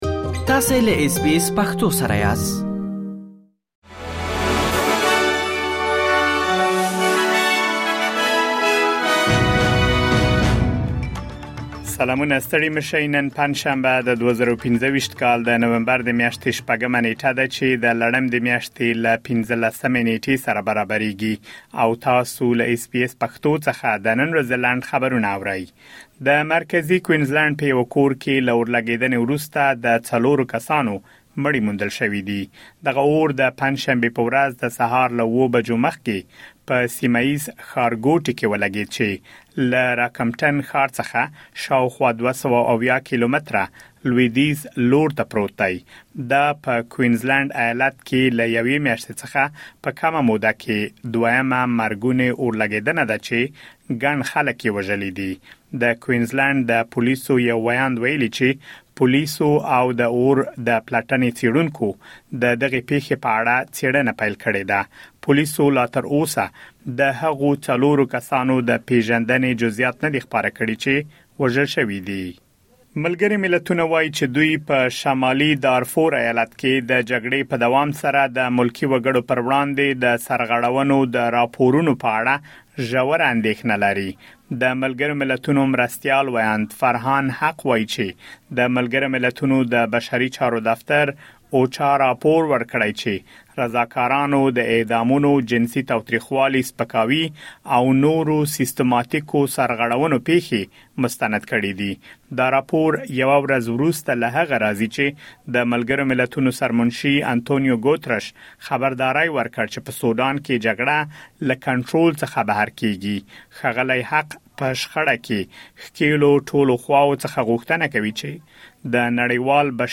د اس بي اس پښتو د نن ورځې لنډ خبرونه |۶ نومبر ۲۰۲۵